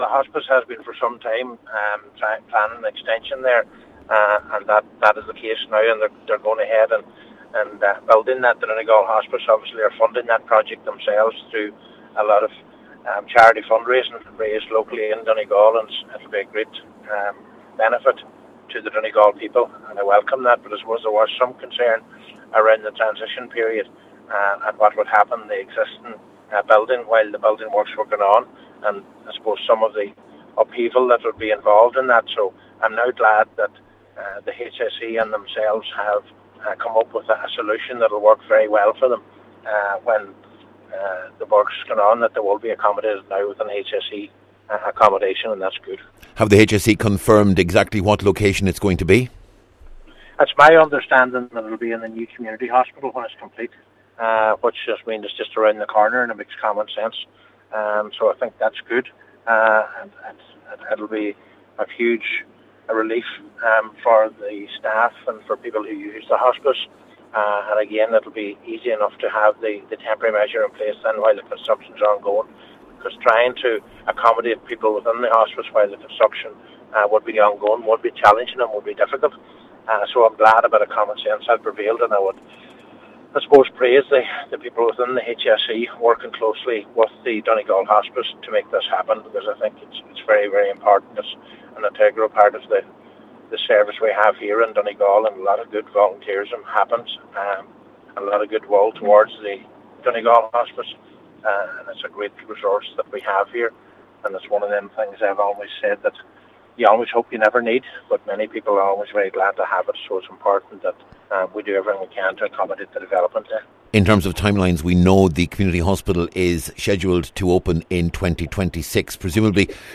He says it’s a significant development.